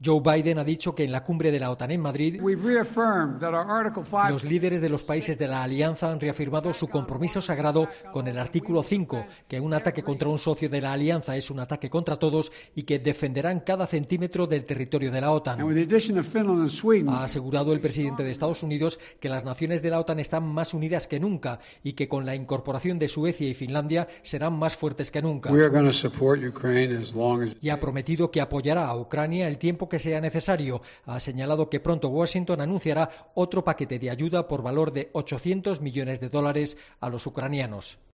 El mandatario apuntó que su Gobierno y los aliados ayudarán a Ucrania el tiempo que sea necesario, en una rueda de prensa al término de la cumbre de la OTAN en Madrid.